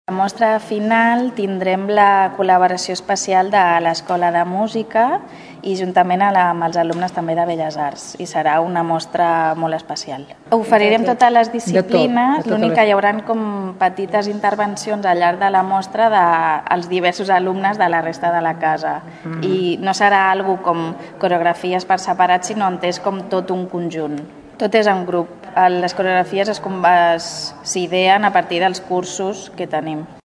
Aquest diumenge, 5 de juny, a les 18:00h els alumnes d’aquesta àrea del Centre de Formació Artística duran a terme la corresponent mostra de fi de curs, a la Sala Gran del Teatre Clavé. Ho expliquen dues de les professores de l’ escola.